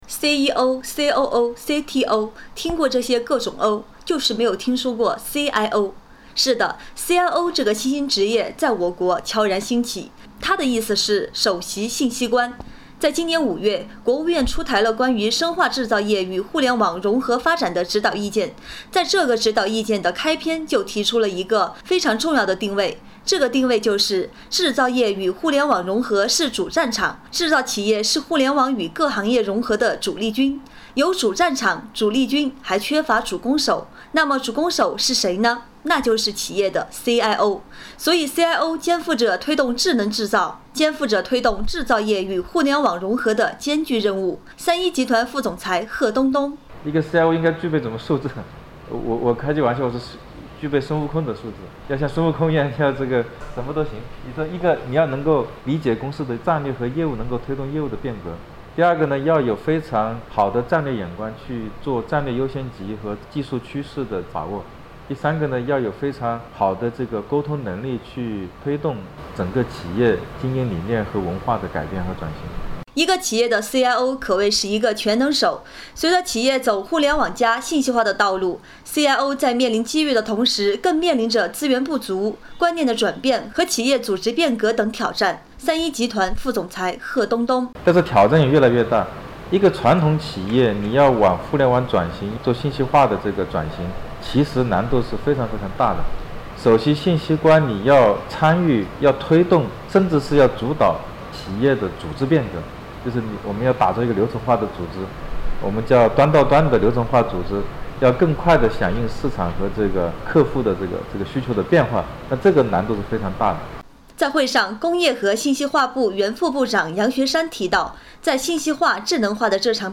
【电台】四川电台新闻频率 FM106.1